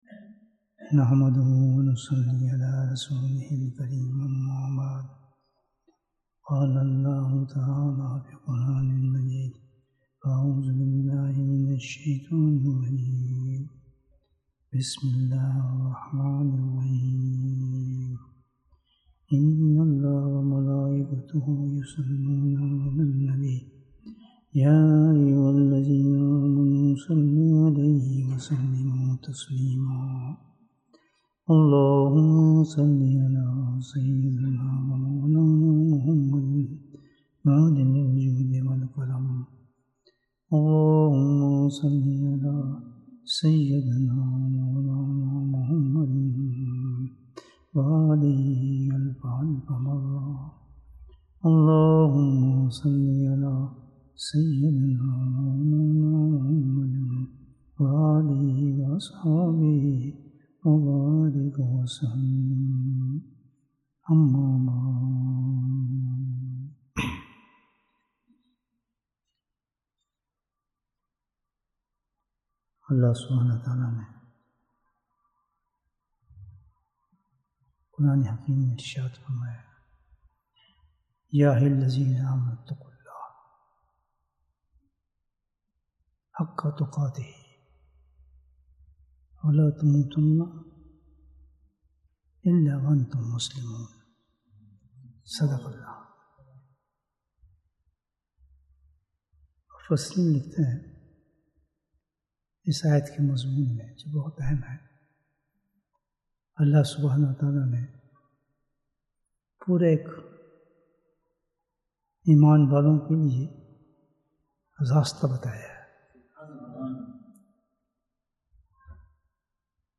تزکیہ کا آسان طریقہ Bayan, 49 minutes1st September, 2022